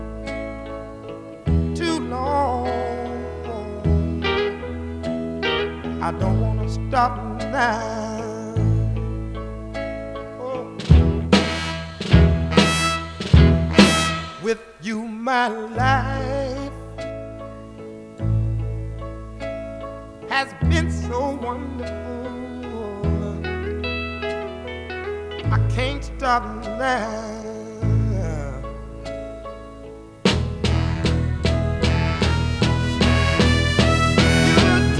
Жанр: Соул / R&b